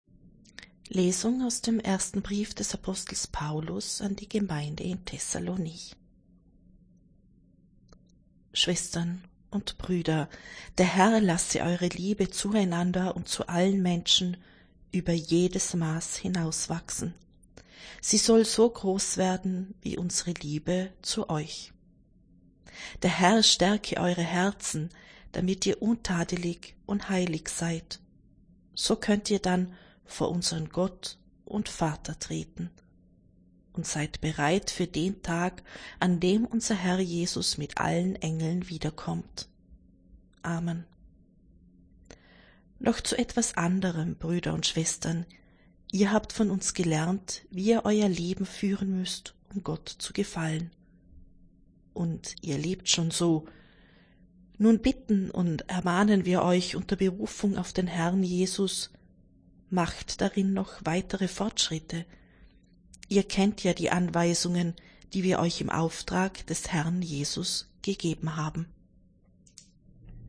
Wenn Sie den Text der 2. Lesung aus dem Brief des Apostels Paulus an die Gemeinde in Thessalónich anhören möchten: